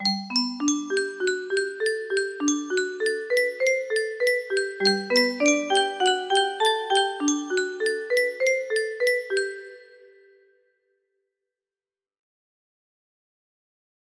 Pequeña Serenata Nocturna de Mozart music box melody